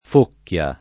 Focchia [ f 1 kk L a ]